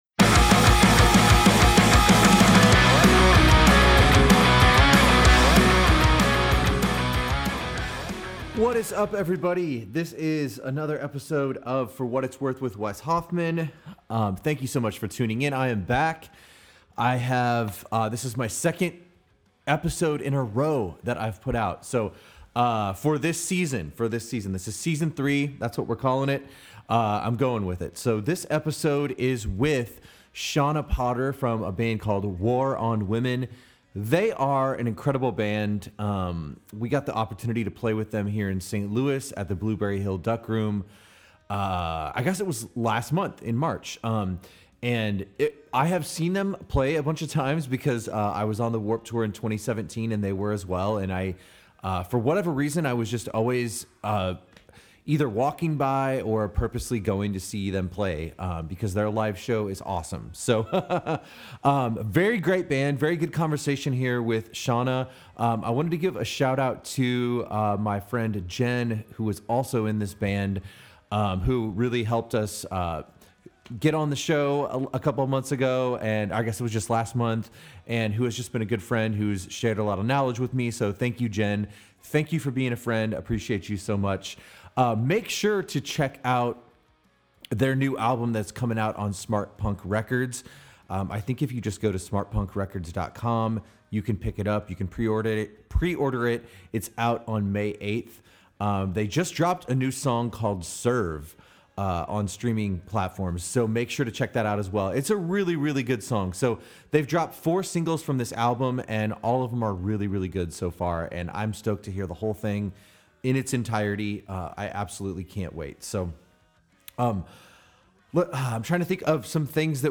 This is an awesome conversation about songwriting, the creative process, touring, and their new album due out May 8th on Smartpunk Records.